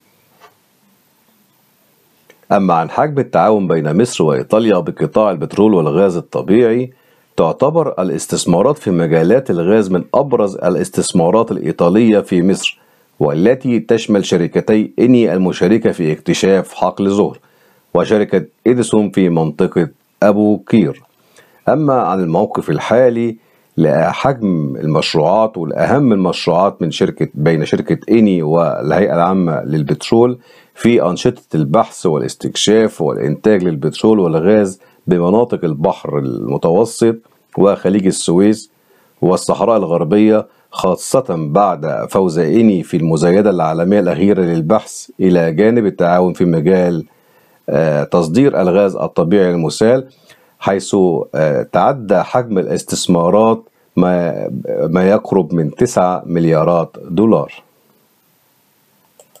محلل اقتصادي